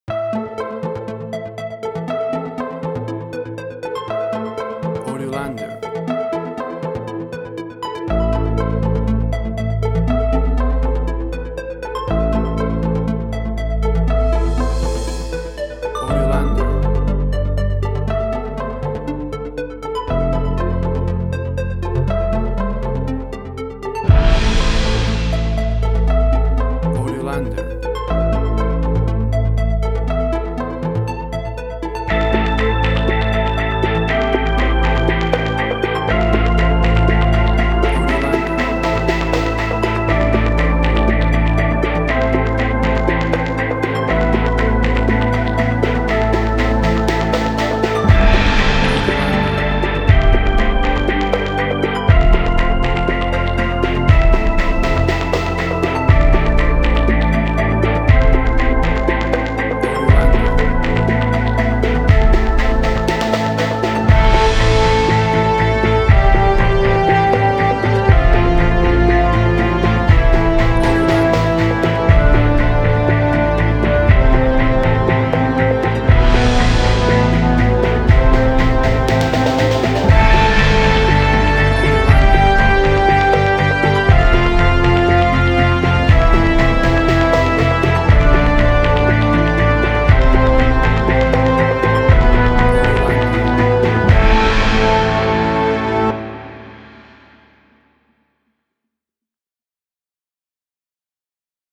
Post-Electronic.
Tempo (BPM): 120